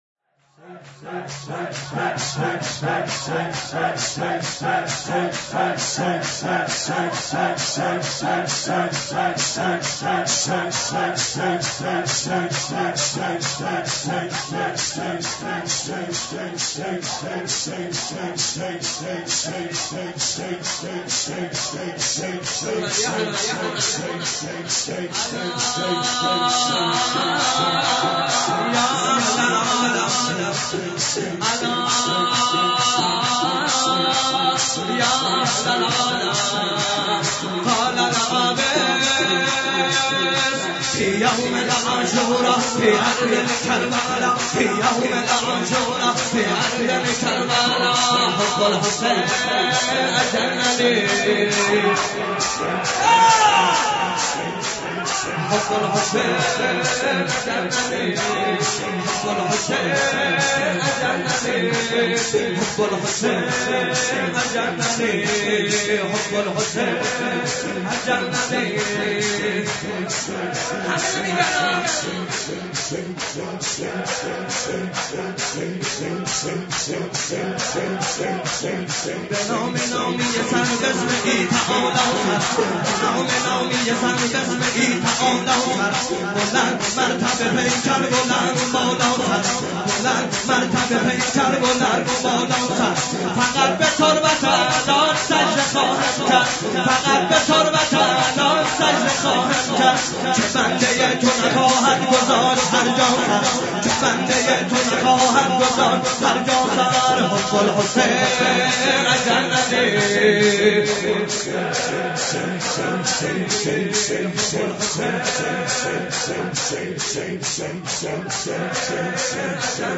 شب هشتم
(شور)